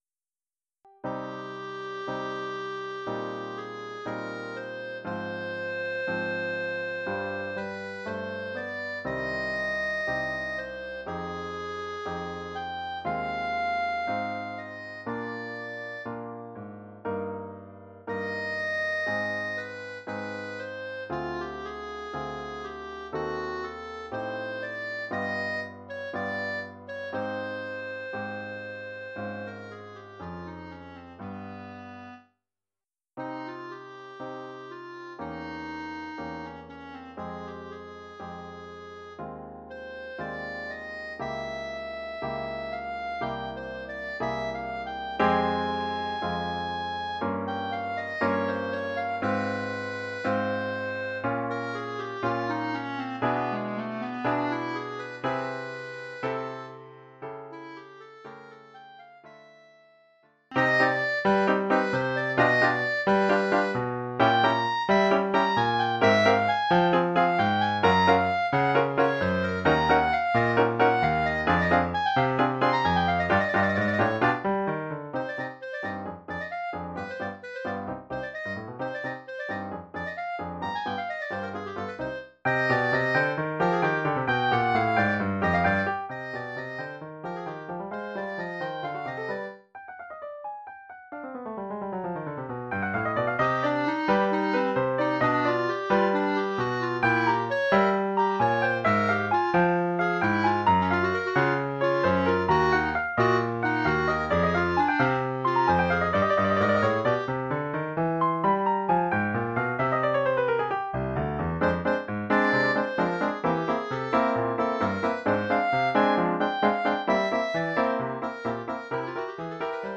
Formule instrumentale : Clarinette et piano
Oeuvre pour clarinette avec
accompagnement de piano.